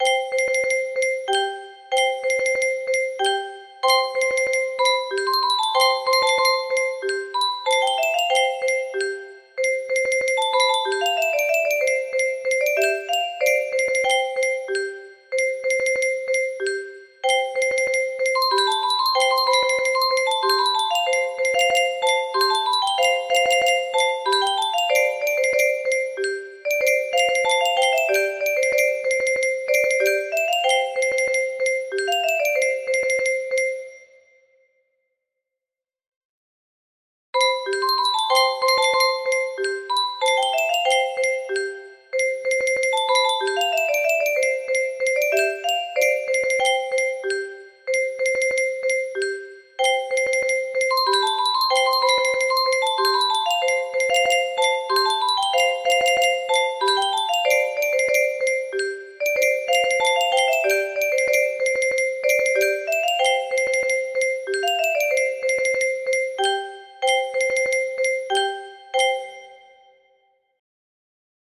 Clone of Bolero music box melody